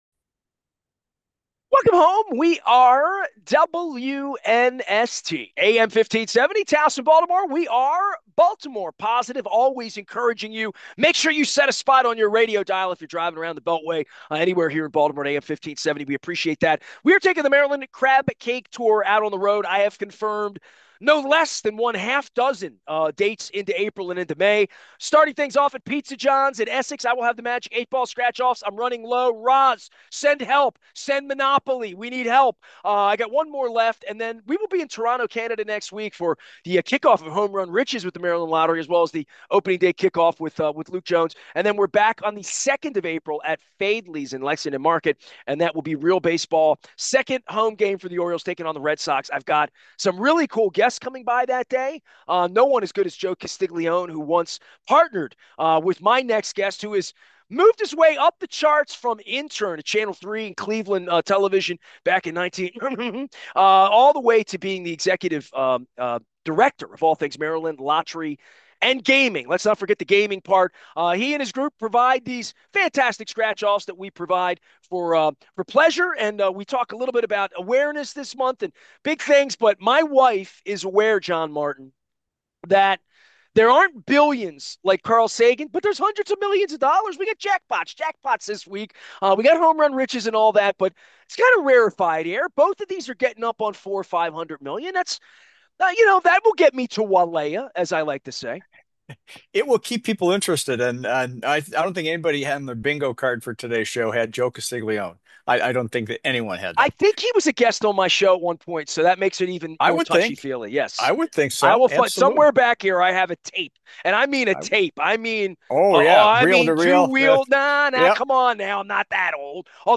Intelligent conversation about all things Baltimore.